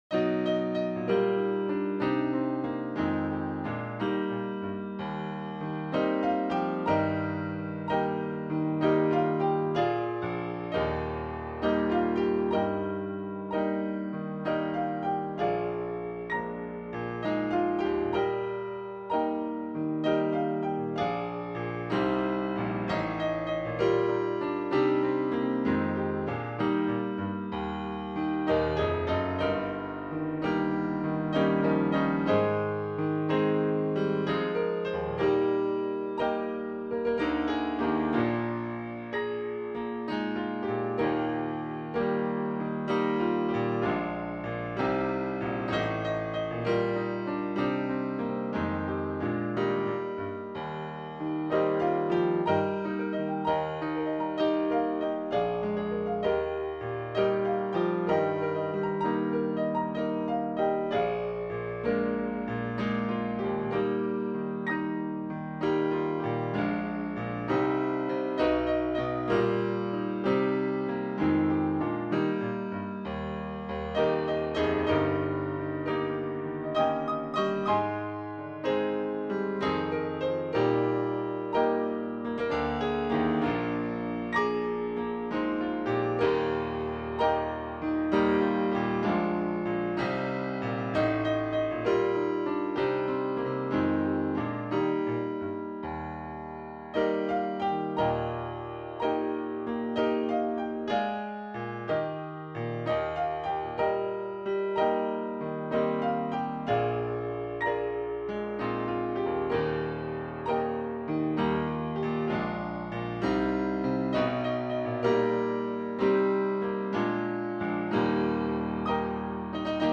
Key: E♭ Meter: 10.9.10.9 D